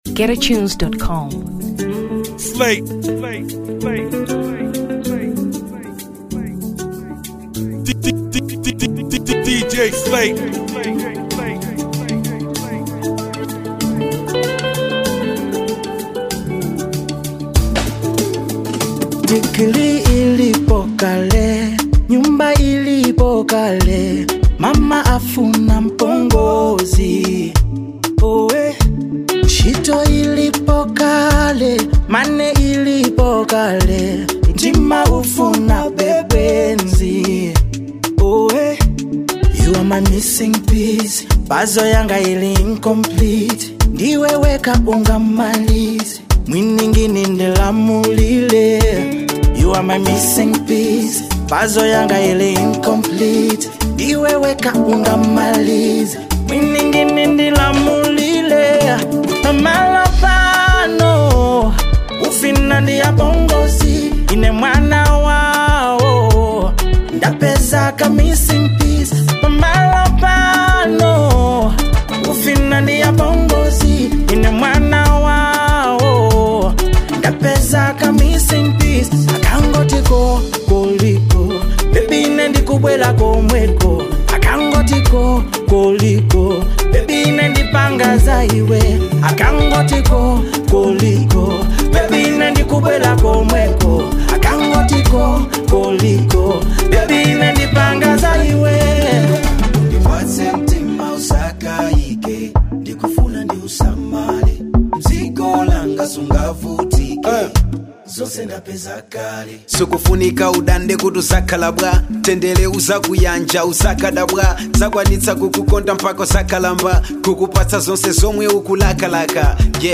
Afrobeat 0 Malawi